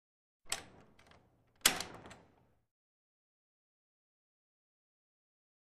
Metal Latch Clicks 1.